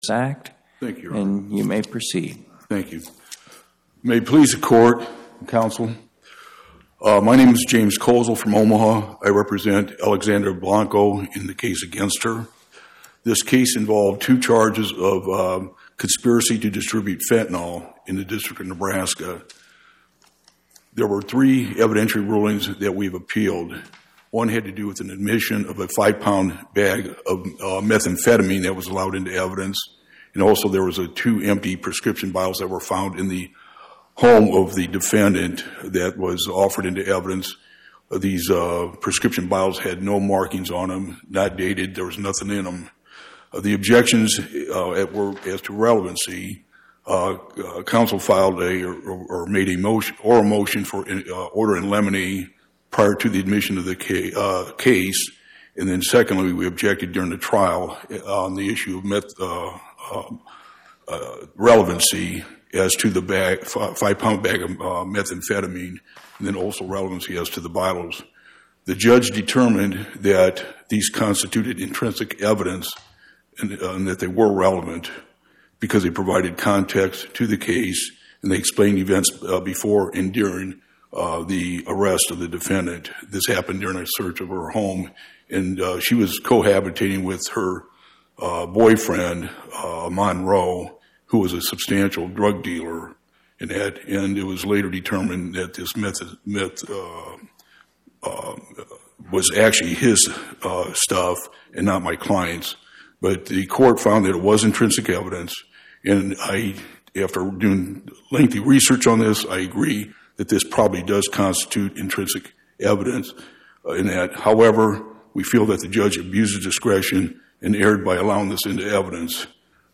Oral argument argued before the Eighth Circuit U.S. Court of Appeals on or about 03/20/2026